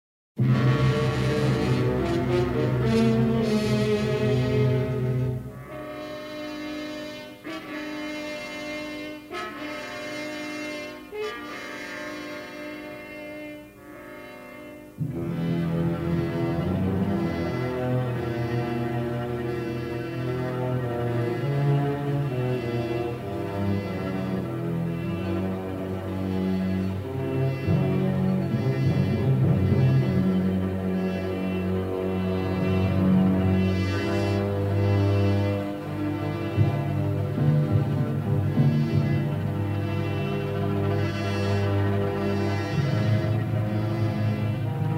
in their definitive stereo editions
Hungarian cymbalom instrument as a flamboyant color
original 1/4" stereo tape